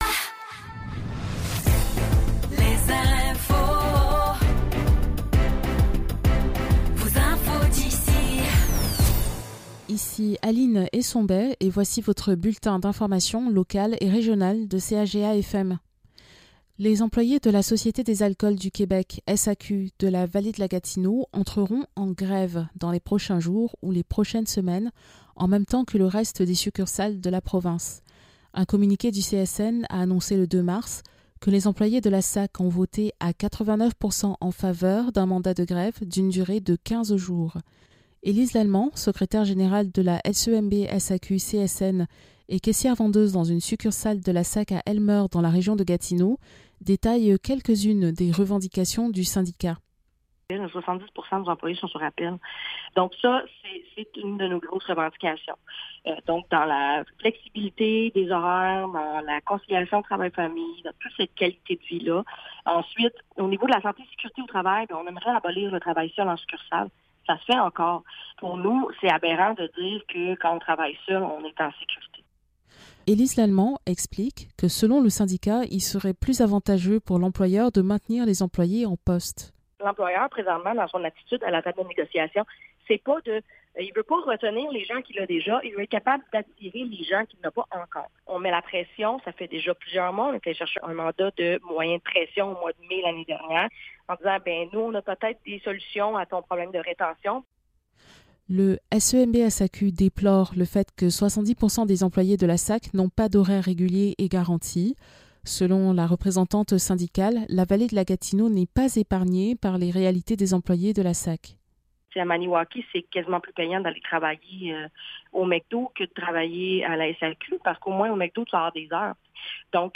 Nouvelles locales - 7 mars 2024 - 12 h